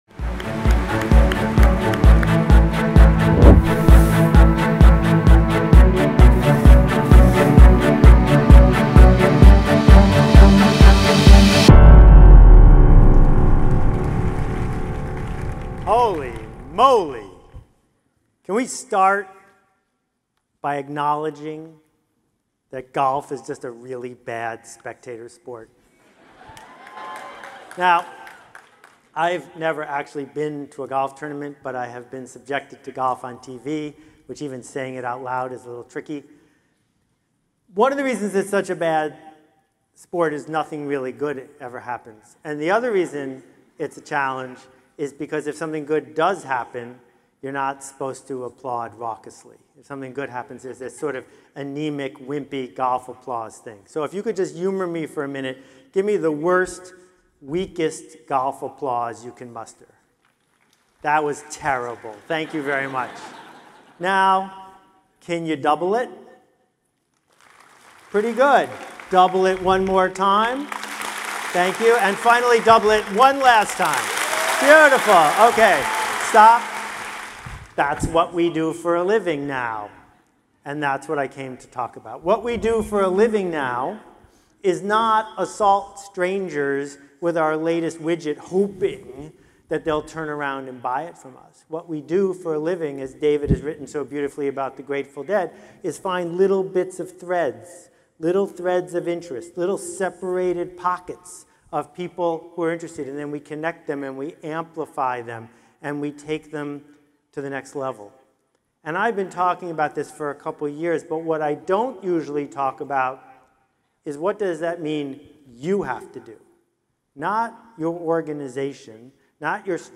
Here's the (free) audio of a recent talk I did at Hubspot Inbound.
inbound-keynote_-seth-godin.mp3